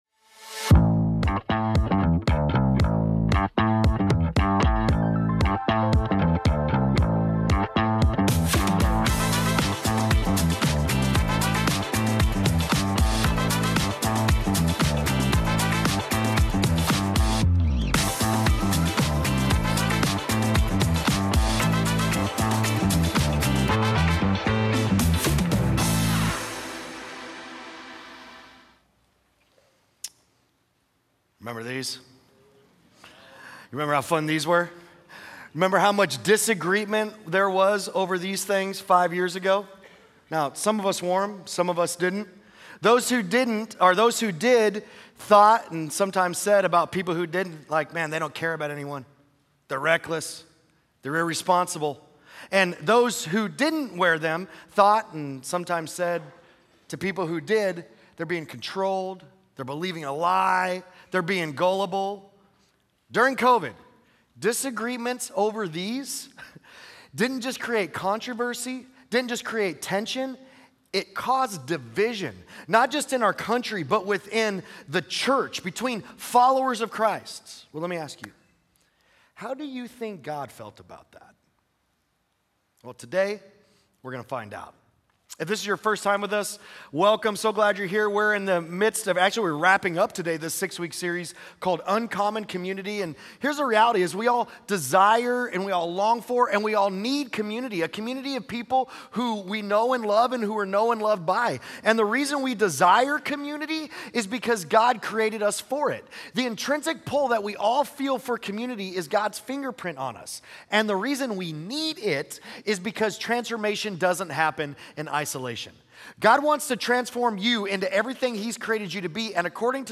Sunday Sermons Uncommon Community, Part 6: "Unity" Oct 12 2025 | 00:36:38 1x 00:00 / 00:36:38 Subscribe Share Apple Podcasts Spotify Overcast RSS Feed Share Link Embed